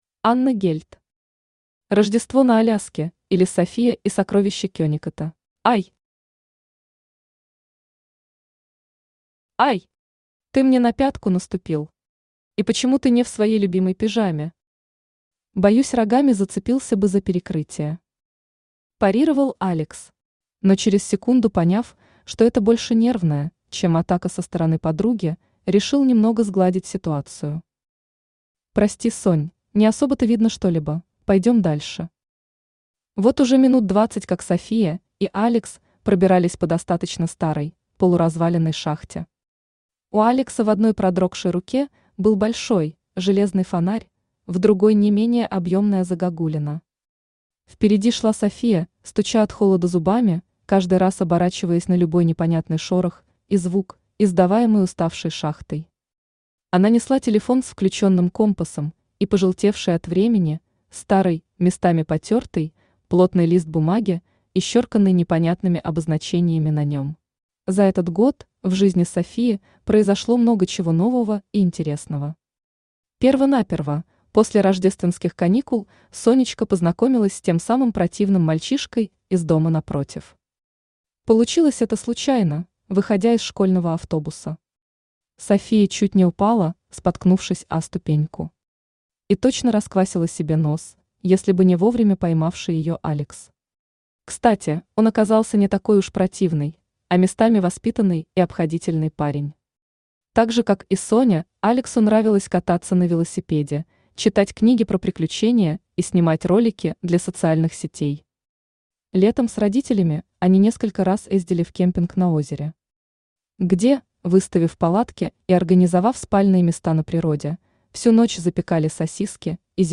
Aудиокнига Рождество на Аляске, или София и сокровища Кеннекотта Автор Анна Гельт Читает аудиокнигу Авточтец ЛитРес.